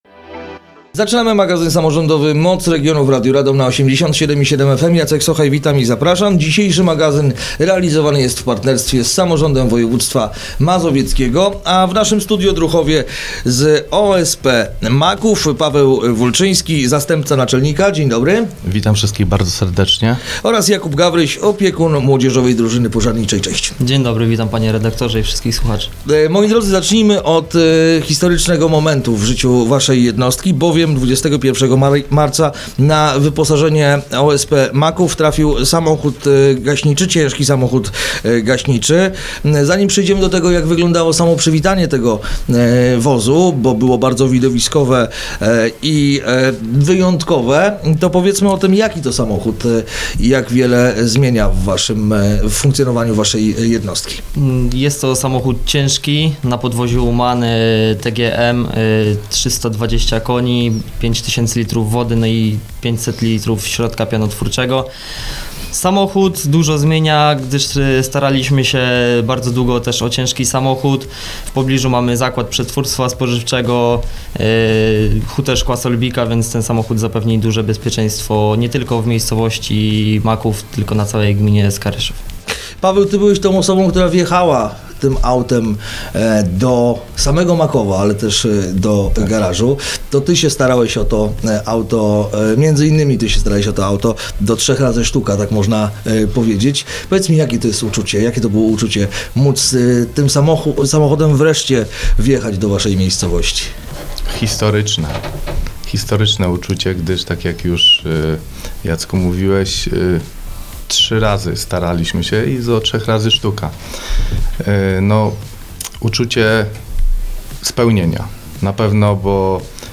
Rozmowa dostępna jest także na facebookowym profilu Radia Radom: